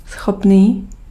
Ääntäminen
Synonyymit capable convenable propice Ääntäminen France: IPA: [apt] Haettu sana löytyi näillä lähdekielillä: ranska Käännös Ääninäyte Adjektiivit 1. schopný Suku: f .